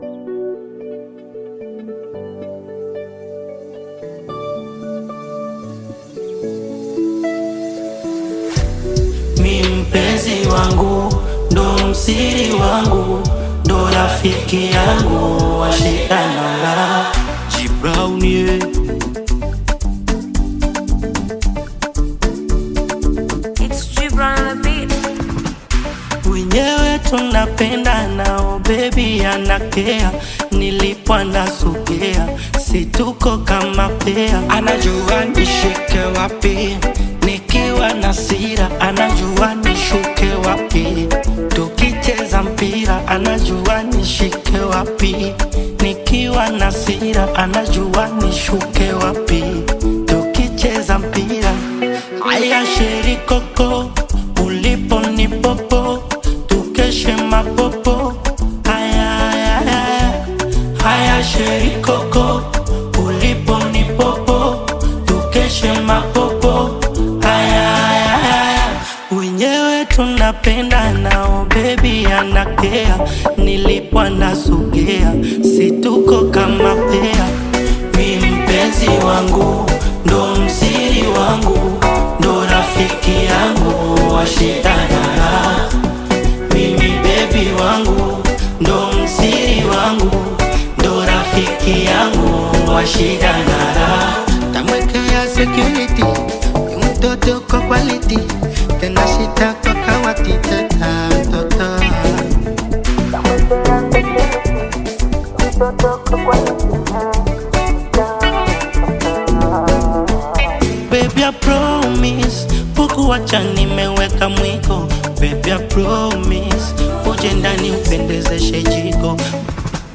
Bongo Flava music track
Tanzanian Bongo Flava artist and singer